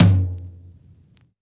Index of /musicradar/Kit 12 - Vinyl
CYCdh_VinylK5-Tom01.wav